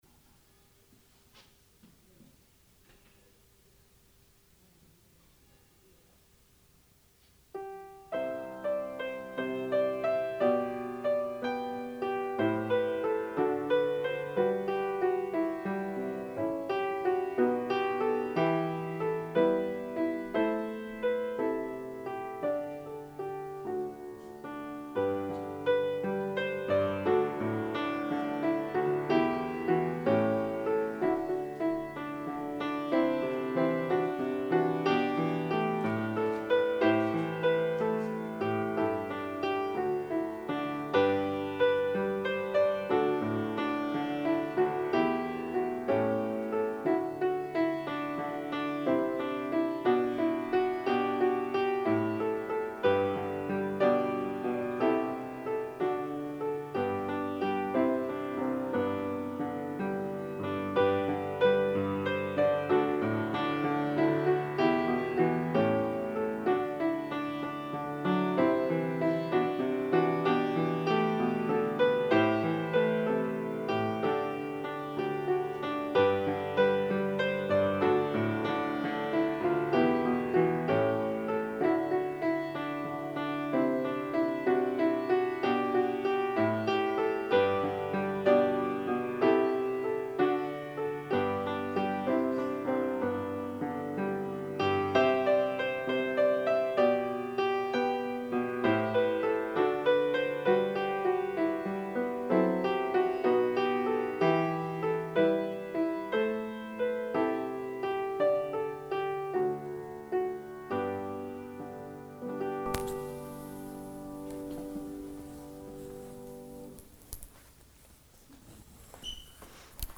Gottesdienst - 25.01.2026 ~ Peter und Paul Gottesdienst-Podcast Podcast